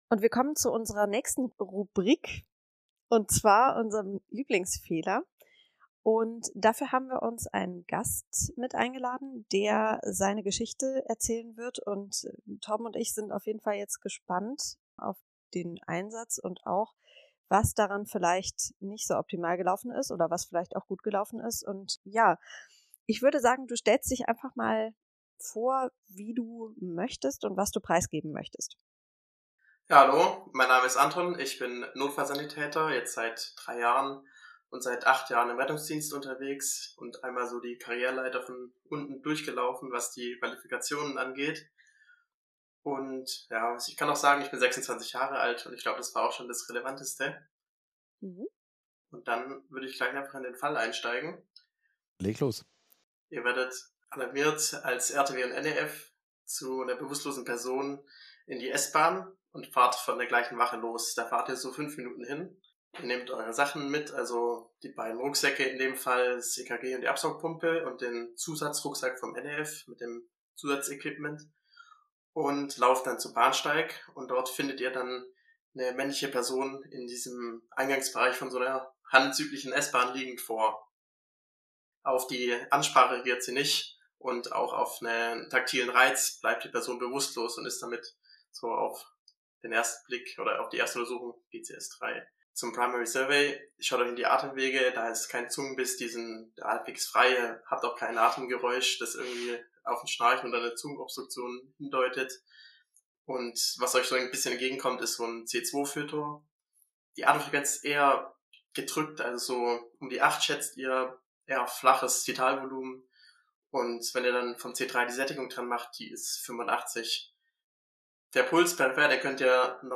Hört rein, wie einer unserer Hörer von seinem Lieblingsfehler im Rettungsdienst berichtet.